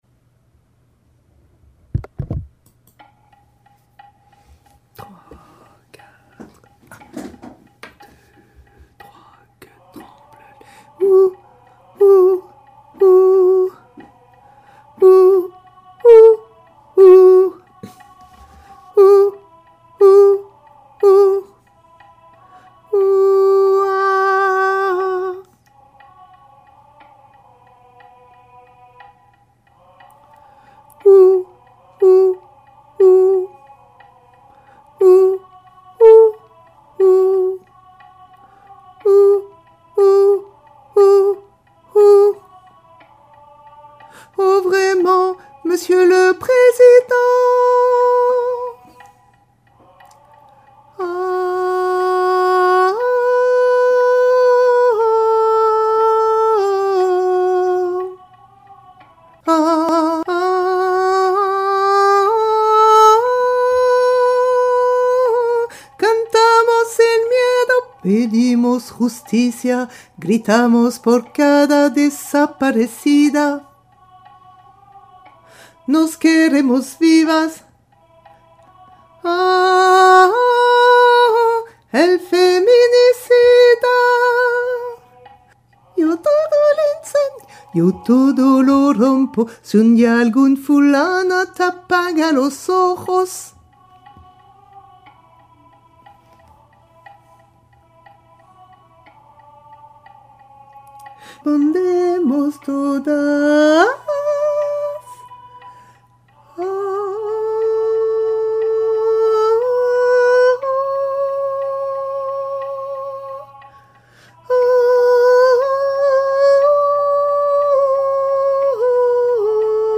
Cancion voix haute
cancion-voix-haute.mp3